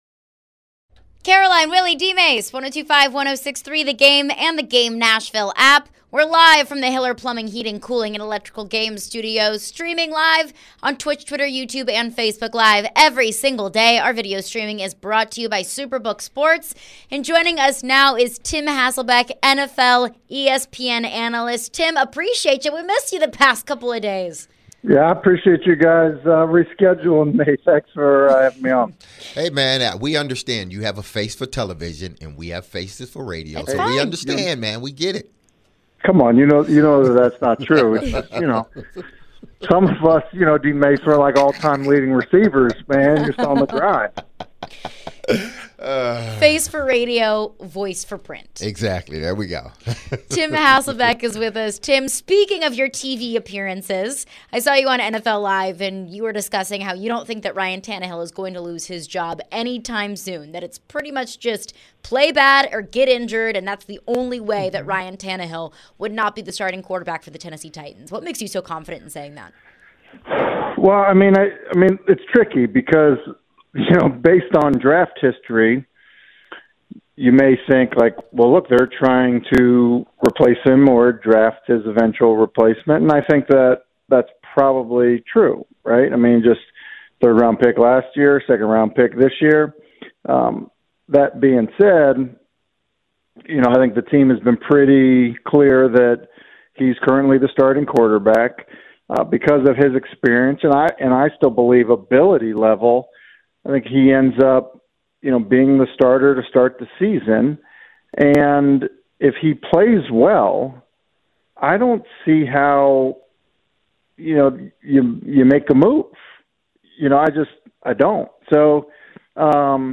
Tim Hasselbeck Interview (5-18-23)